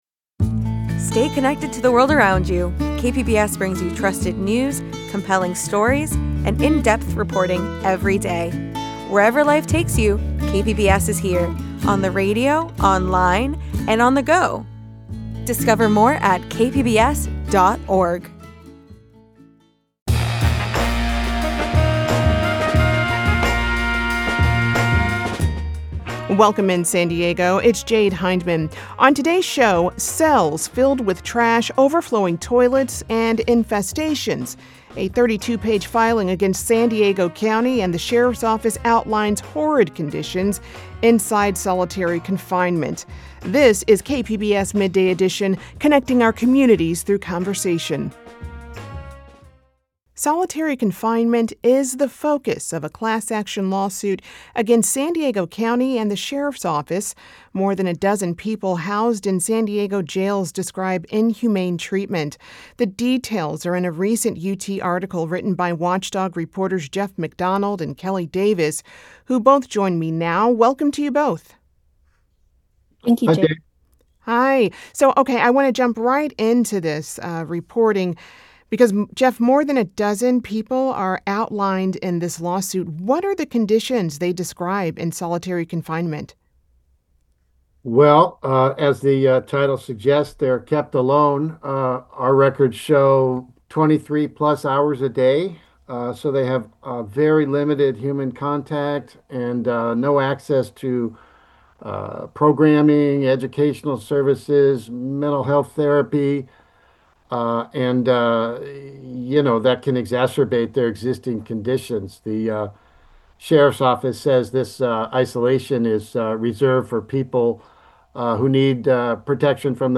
Guests share diverse perspectives from their expertise and lived experience.
… continue reading 1257 episodes # News # KPBS # San Diego # Politics # News Talk